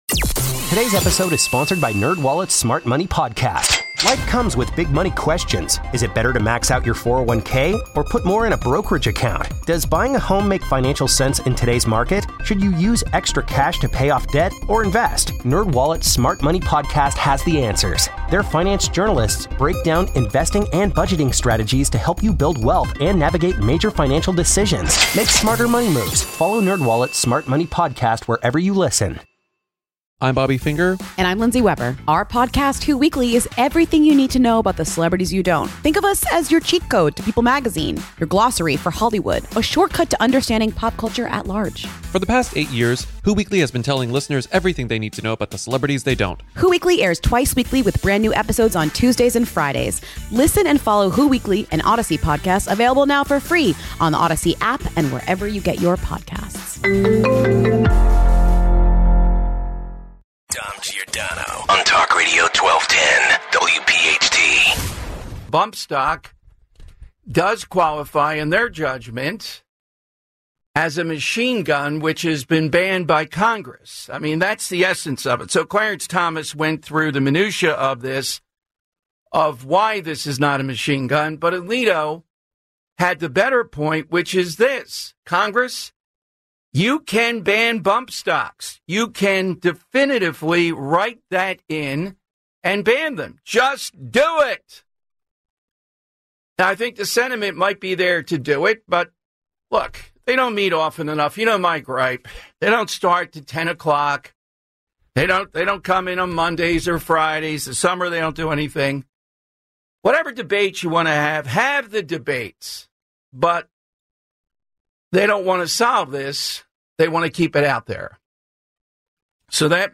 Full Hour